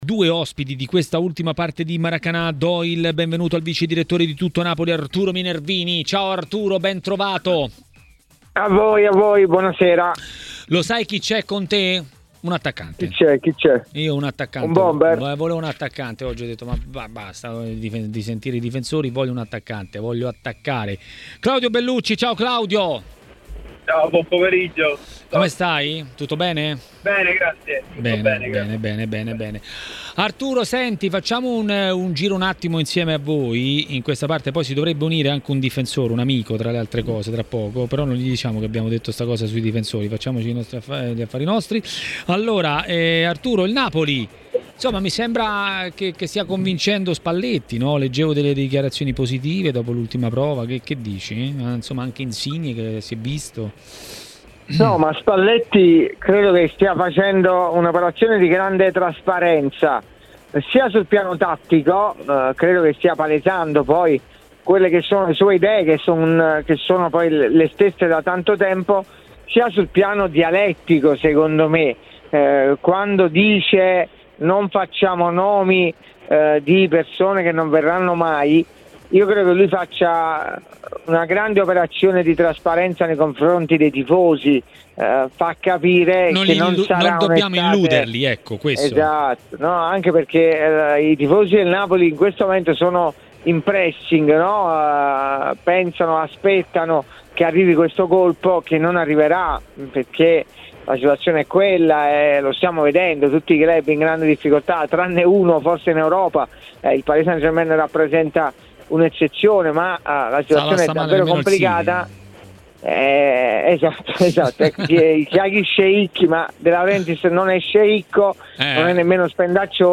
L'ex calciatore Claudio Bellucci a Maracanà, nel pomeriggio di TMW Radio, ha parlato della nuova Serie A che sta per partire.